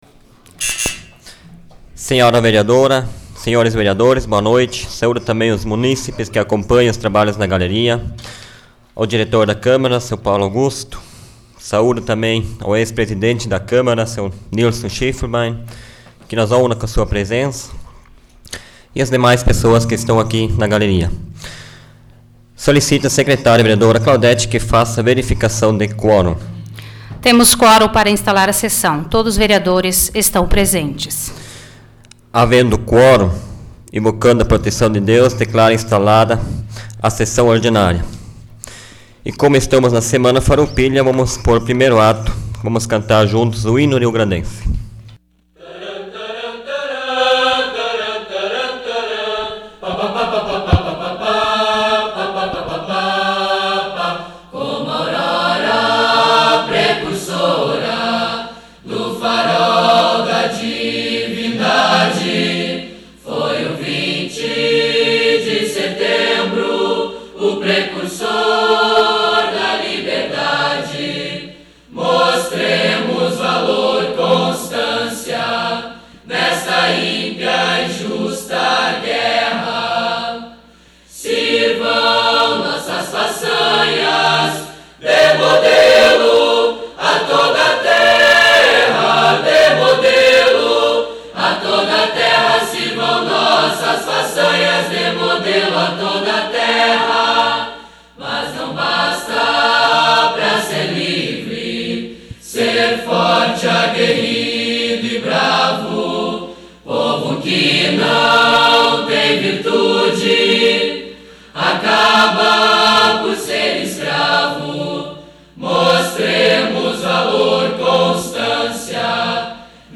Áudio da 61ª Sessão Plenária Ordinária da 12ª Legislatura, de 18 de setembro de 2006